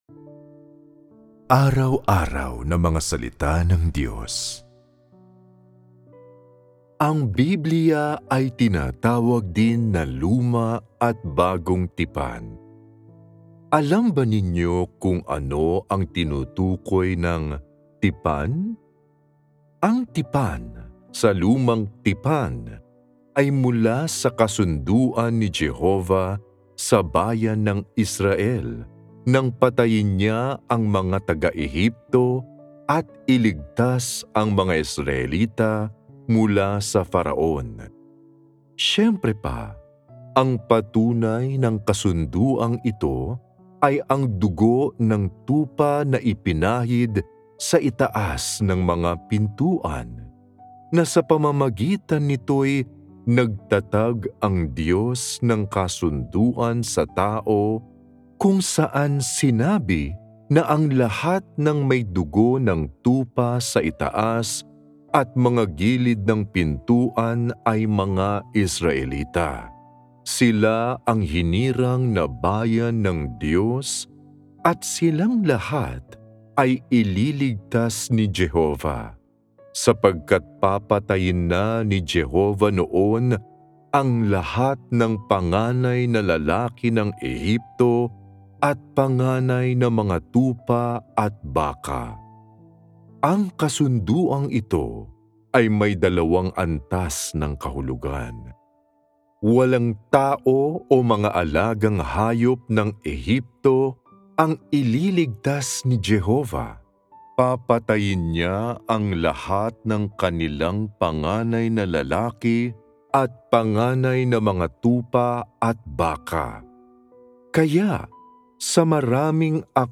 recital-mysteries-about-the-bible-270.m4a